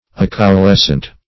Acaulescent \Ac`au*les"cent\, a. [Pref. a- not + caulescent.]